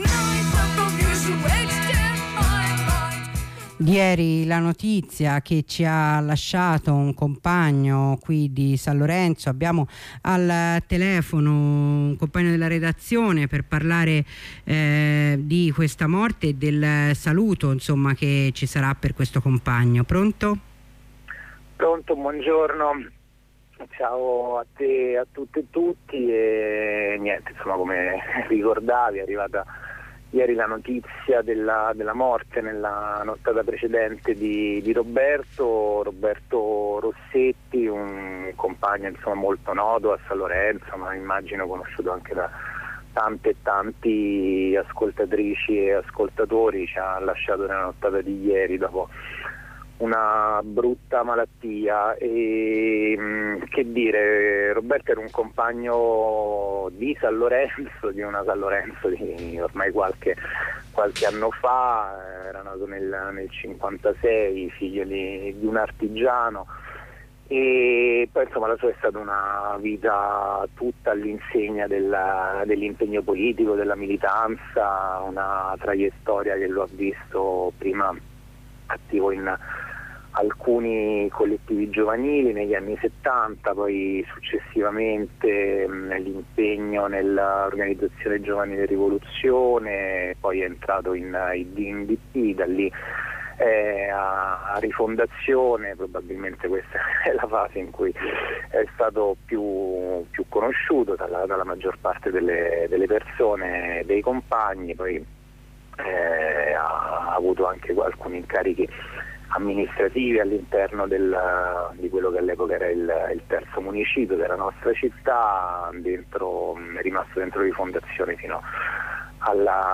Il ricordo di un compagno della redazione di ROR